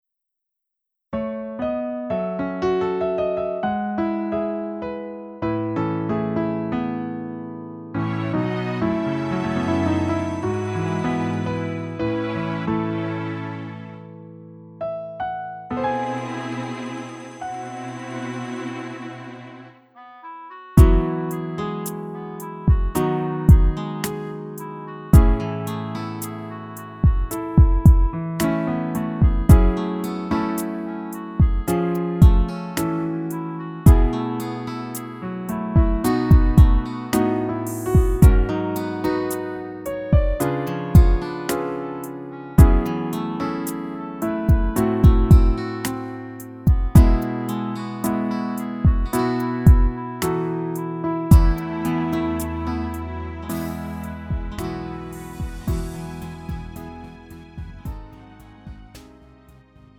음정 원키 4:28
장르 가요 구분 Lite MR